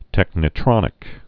(tĕknĭ-trŏnĭk)